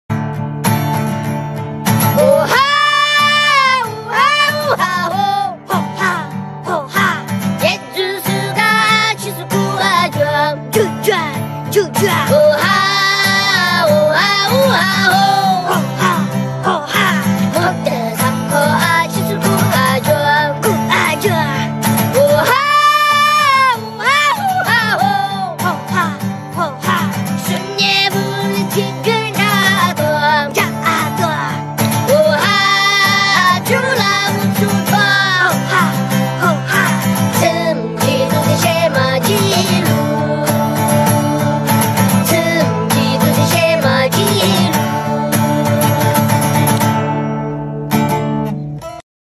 • Качество: 320, Stereo
гитара
веселые
смешные
3 Little Guys Singing